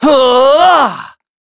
Evan-Vox_Casting3.wav